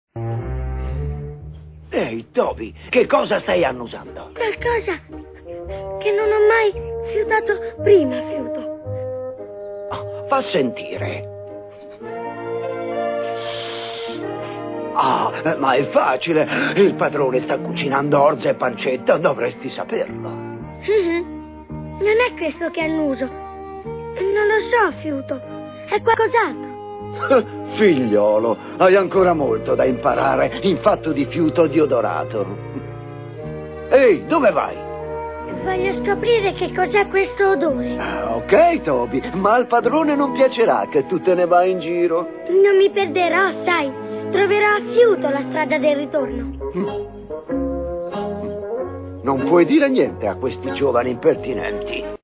nel film d'animazione "Red e Toby nemici amici", in cui doppia Vecchio Fiuto.